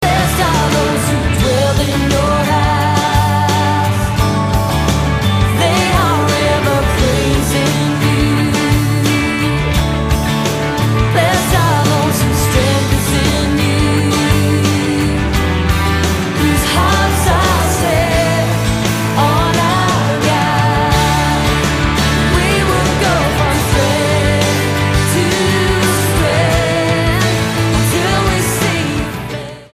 STYLE: Pop
The title track has an atmospheric build-up